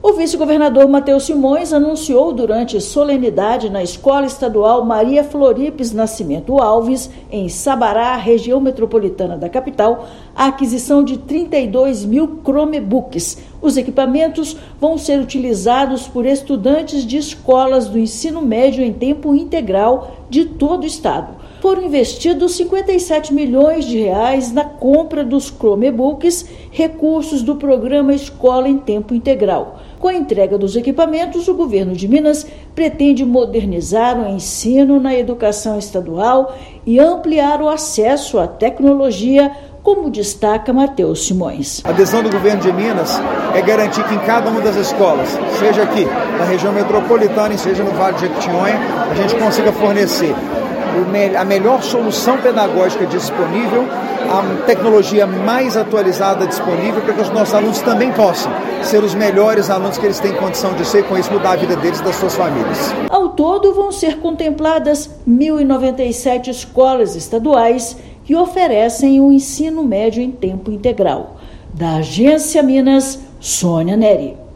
Equipamentos serão usados dentro das unidades para fortalecer práticas pedagógicas inovadoras e ampliar o acesso à cultura digital. Ouça matéria de rádio.